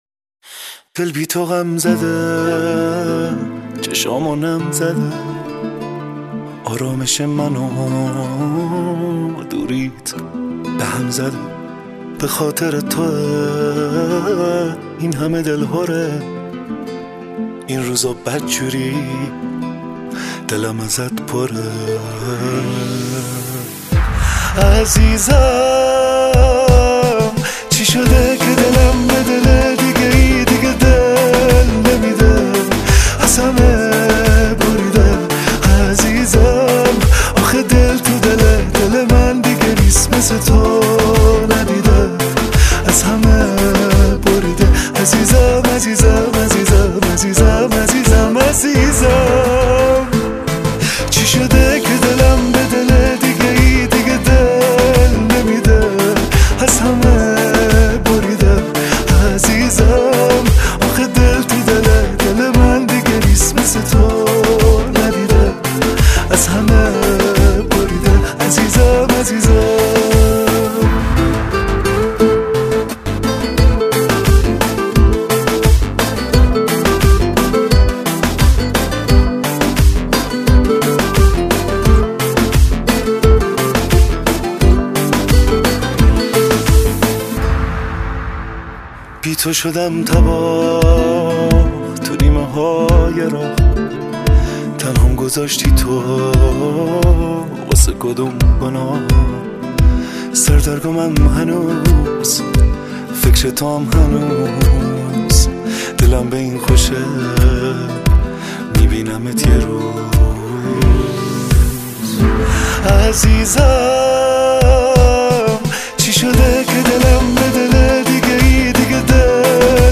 دسته : سنتی ایرانی